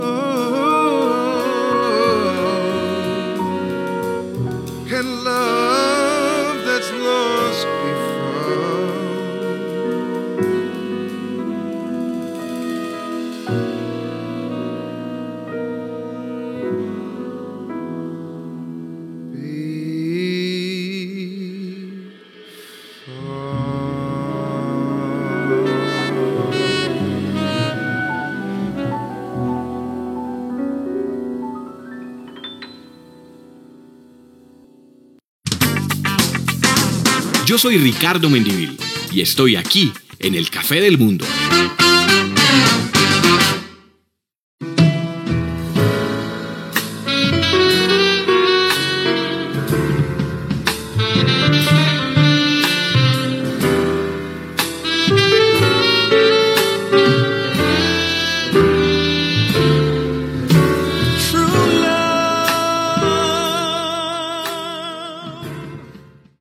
Tema musical, indicatiu de l'emissora i tema musical